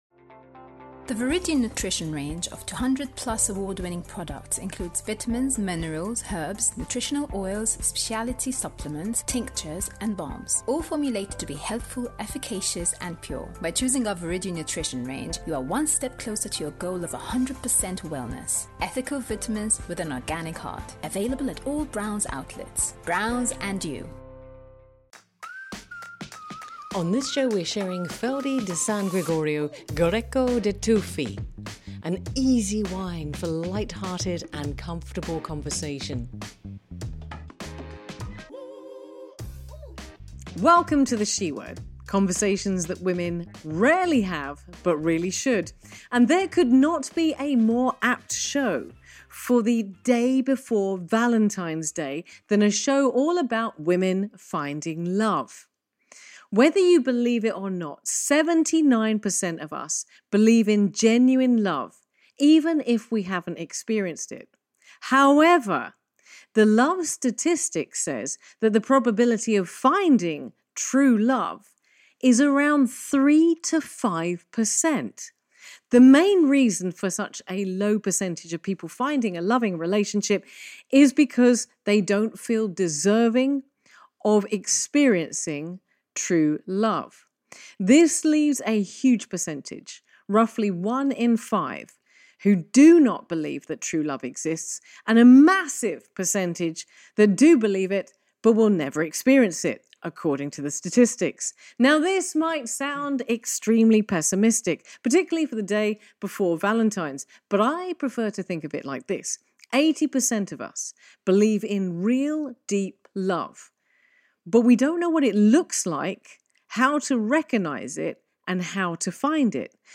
In this lighthearted and entertaining episode, The SHE Word looks at the timely topic of finding LOVE.